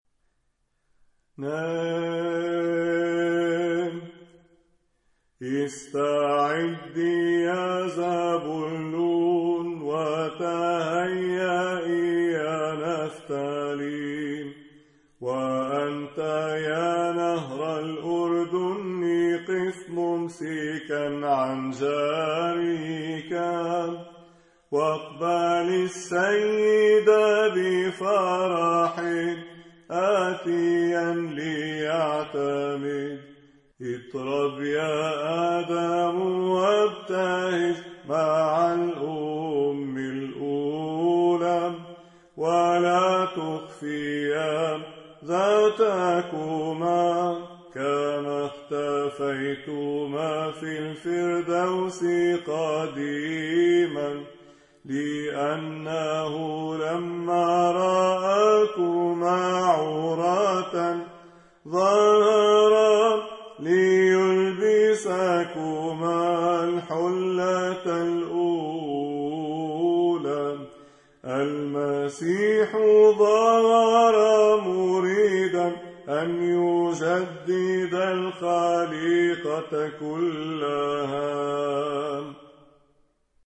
تراتيل الظّهور الإلهي